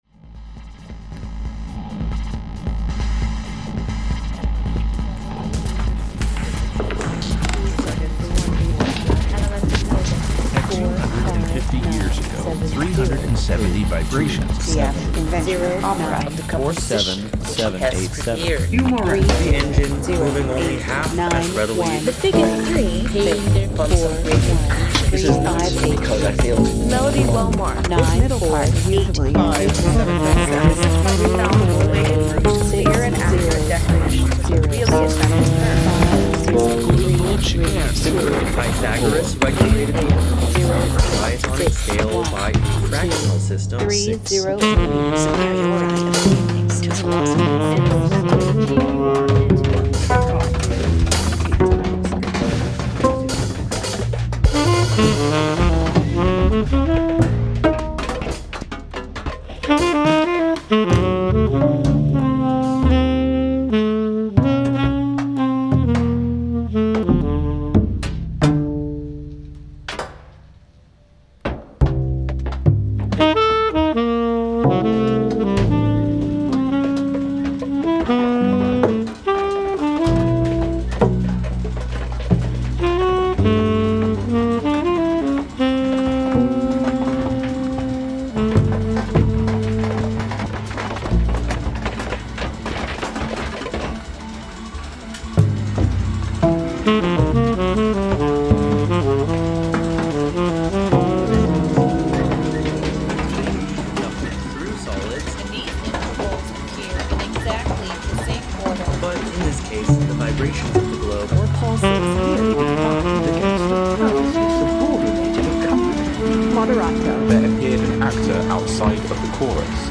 A late night live music show with teeth (and horns).